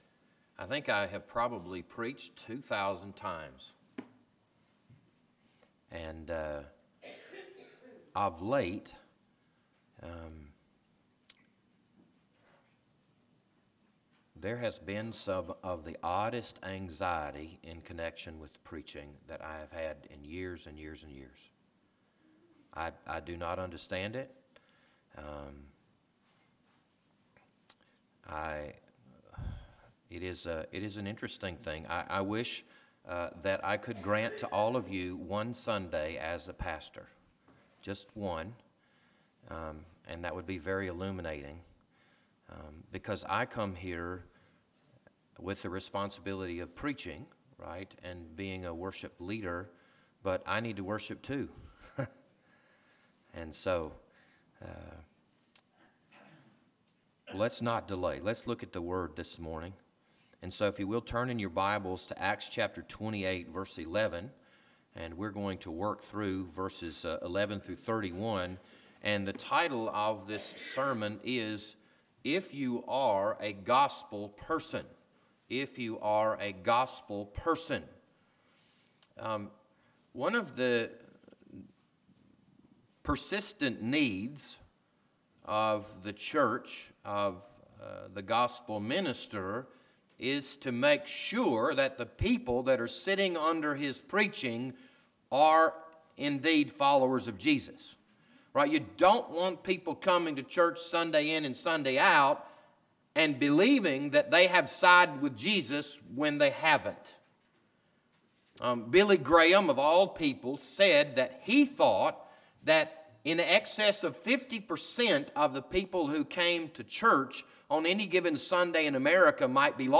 This Sunday morning sermon was recorded on February 18, 2018.